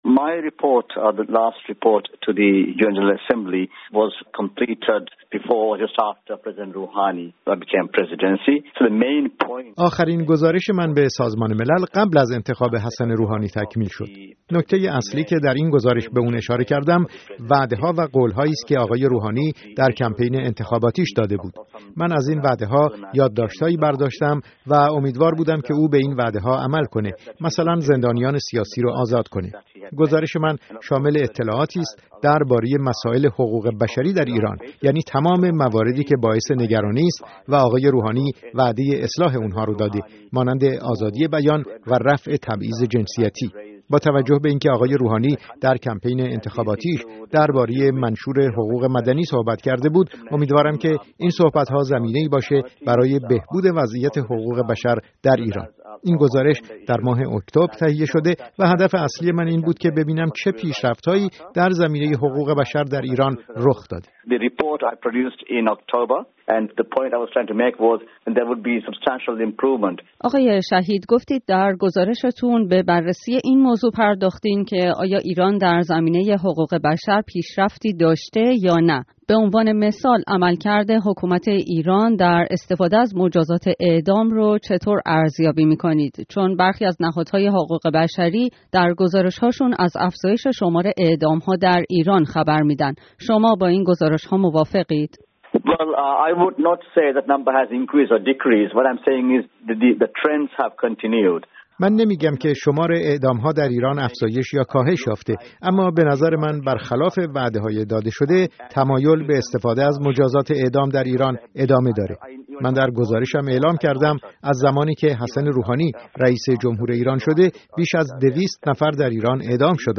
در آستانه روز جهانی حقوق بشر، در گفت‌وگویی با احمد شهید گزارشگر ویژه سازمان ملل در امور حقوق بشر در ایران، ابتدا از او درباره مهم‌ترین مواردی پرسیده‌ایم که او در گزارش خود به آن‌ها پرداخته است: